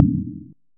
impactMetal_003.ogg